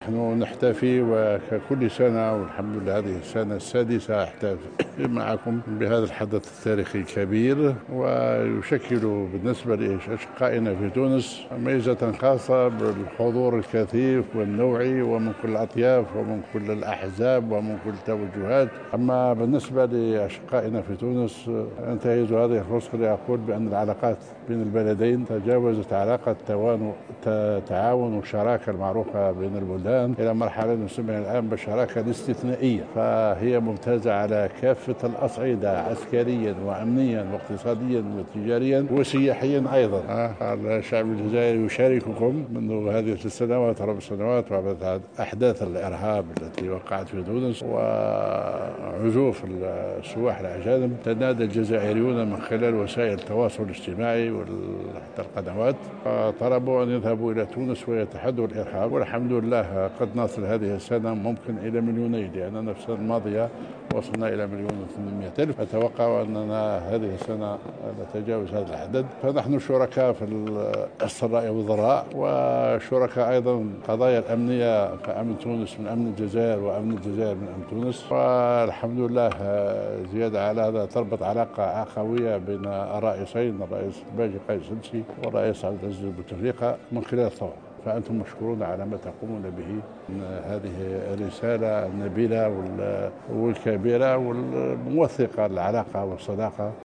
جدّد سفير الجزائر بتونس عبد القادر حجار، بمناسبة إحياء الذكرى 63 للثورة الجزائرية اليوم بمقر سفارة الجزائر بتونس، تأكيده متانة العلاقات التونسية الجزائرية، التي بلغت مرحلة الشراكة الاستثنائية، وفق تعبيره.
وأضاف في تصريح لمراسلة الجوهرة اف ام، أن التعاون بين البلدين على جميع الأصعدة وخاصة على المستويين العسكري والأمني، وكذلك اقتصاديا وتجاريا وسياحيا، في أعلى مستوياته.